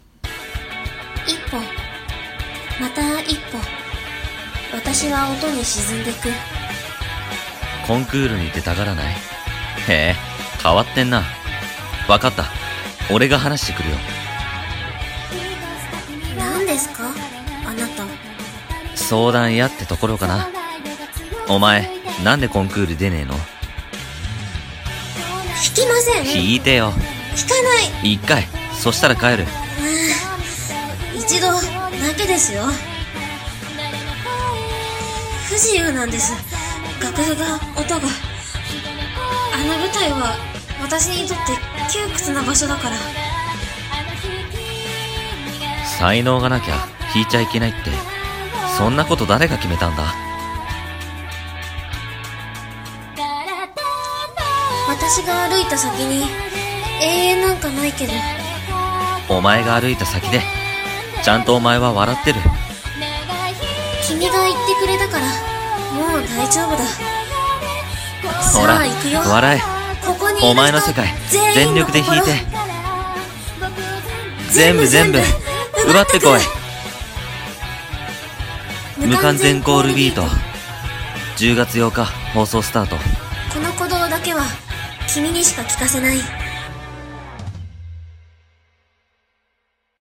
アニメCM風声劇